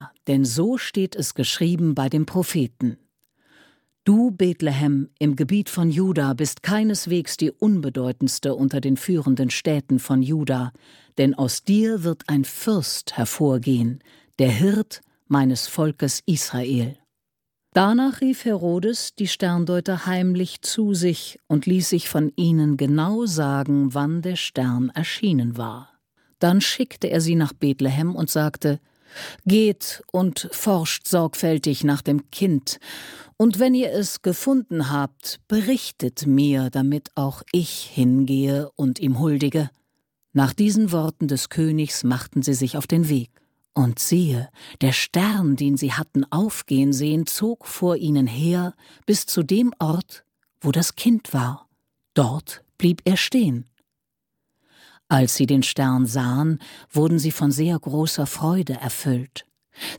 Die Hörbibel - Einheitsübersetzung (Hörbuch/Hörspiel - MP3-CD)